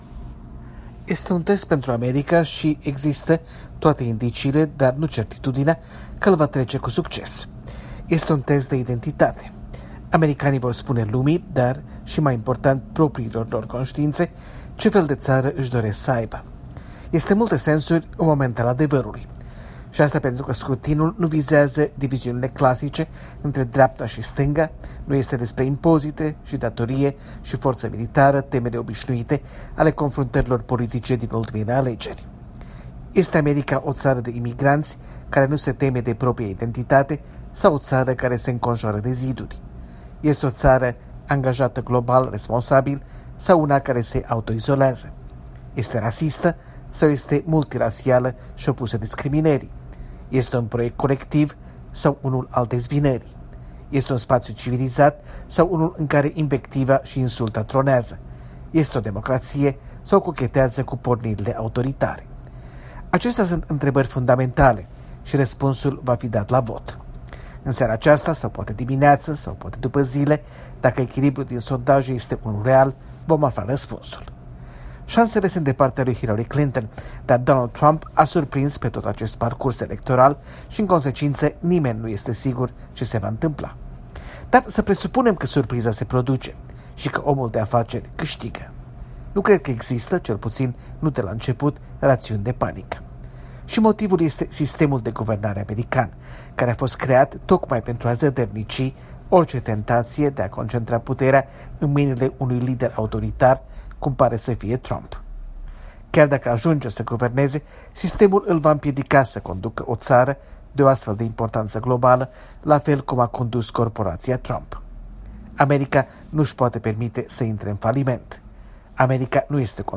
În direct de la Washington: Alegeri prezidențiale în Statele Unite